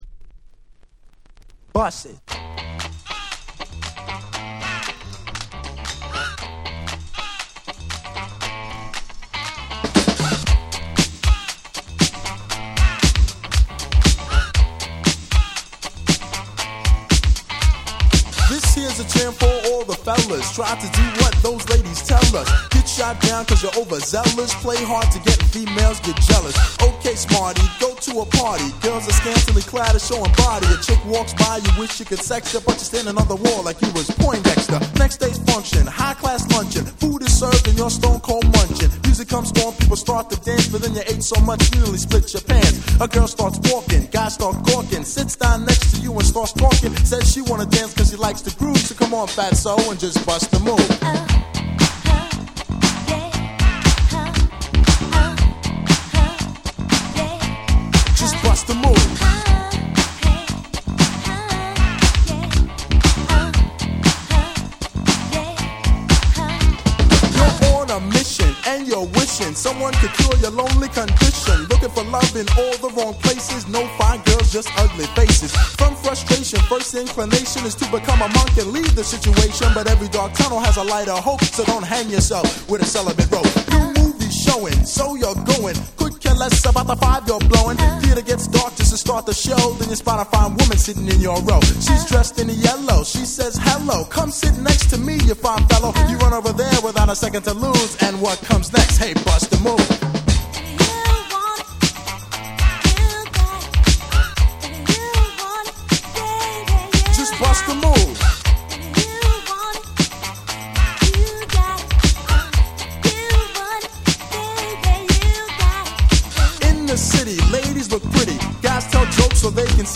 89' Super Hit Hip Hop !!
80年代後期を代表するHip Hopヒットです！！